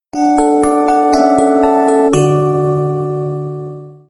Мелодии на звонок
Нарезка на смс или будильник